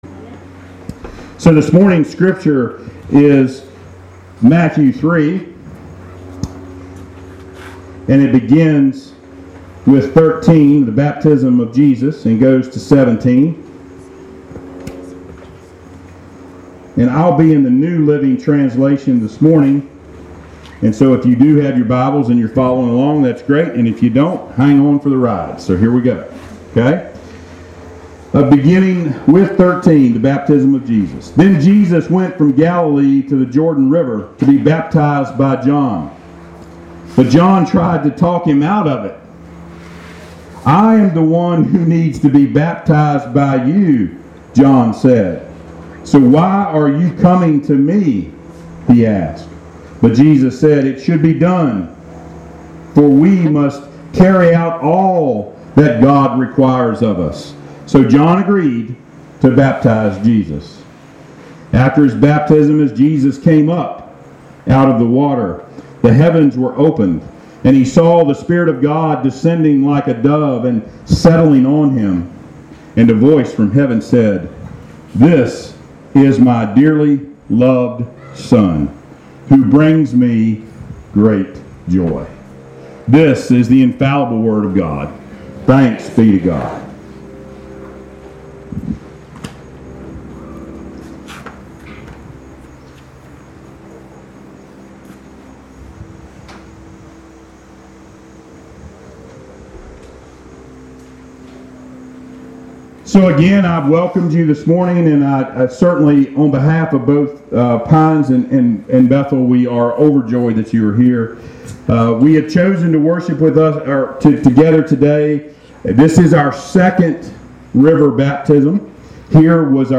Passage: Matthew 3:13-17 Service Type: Sunday Worship